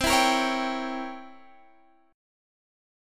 C7b9 chord